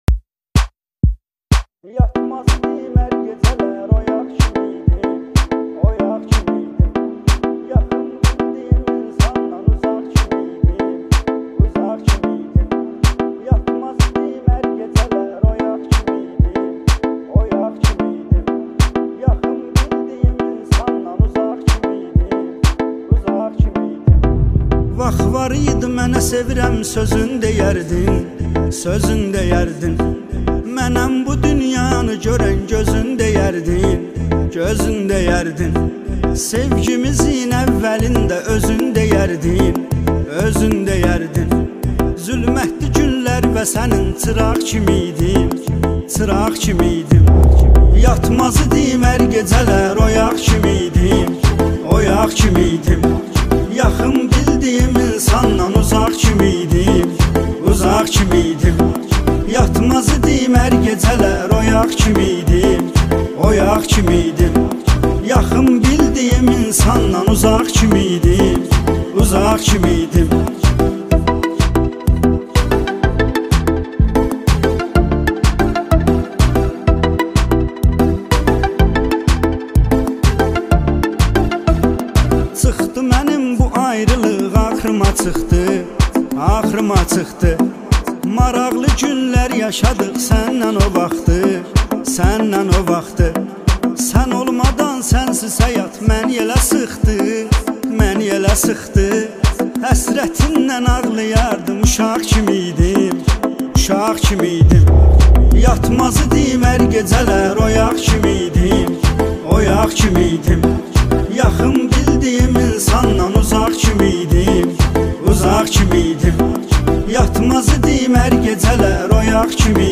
Турецкая музыка